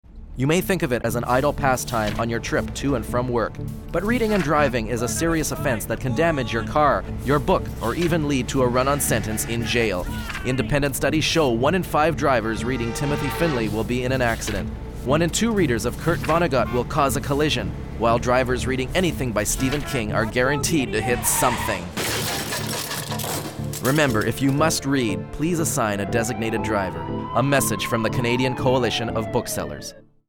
VOICEOVER ON READING WHILE DRIVING